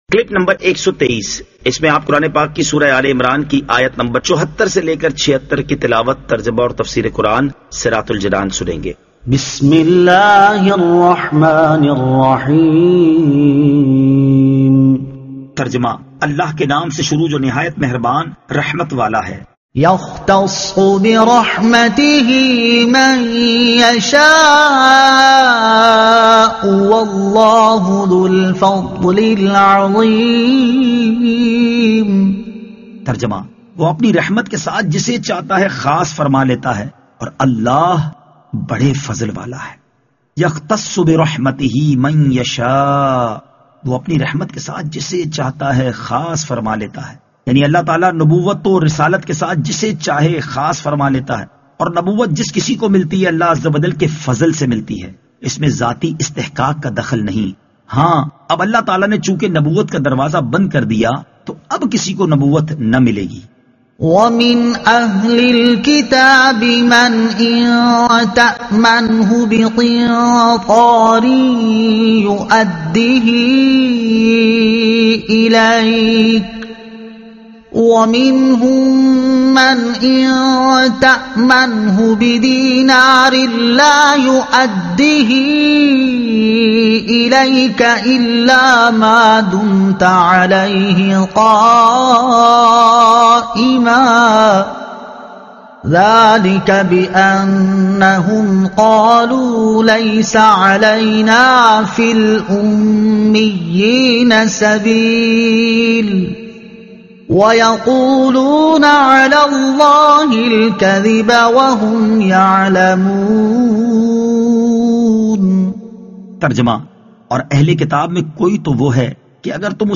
Surah Aal-e-Imran Ayat 74 To 76 Tilawat , Tarjuma , Tafseer